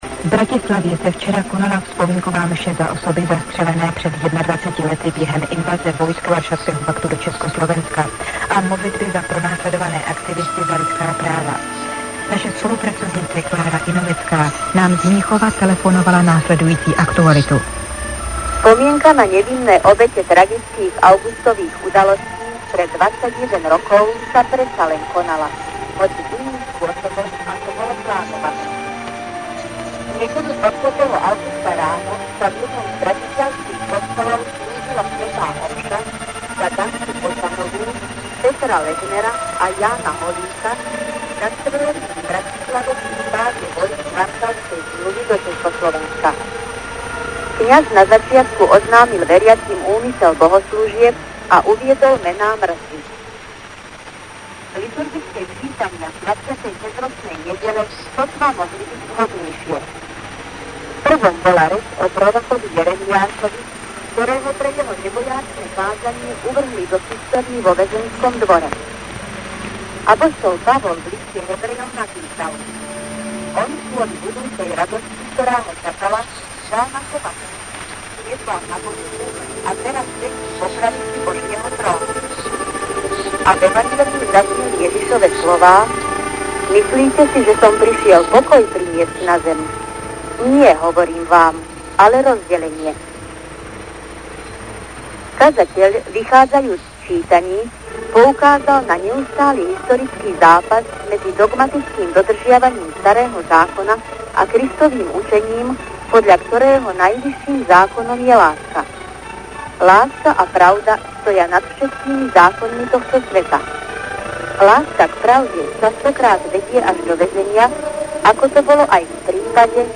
Z vysielania Hlasu Ameriky a Rádia Slobodná Európa  (august – november 1989)    August 1989